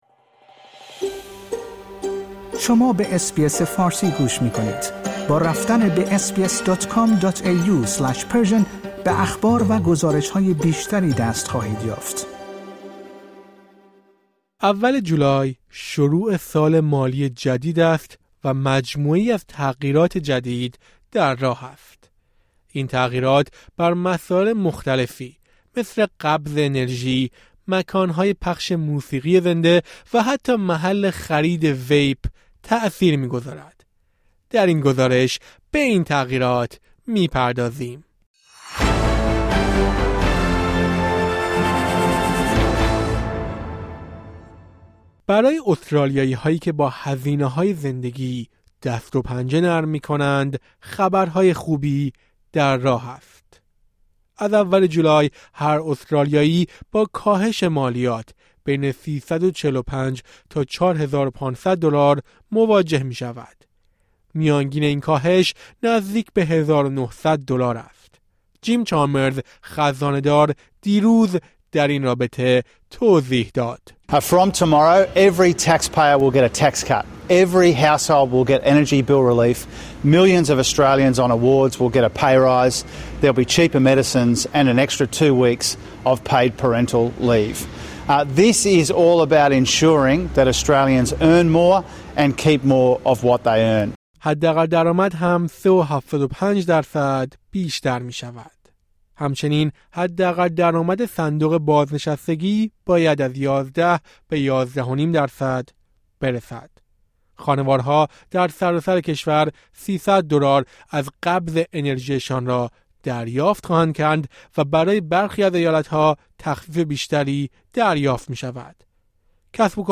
اول جولای شروع سال مالی جدید است و مجموعه‌ای از تغییرات جدید در راه است؛ از افزایش درآمد تا بازپرداخت‌های قبض‌های انرژی. در این گزارش به مهمترین این تغییرات می‌پردازم.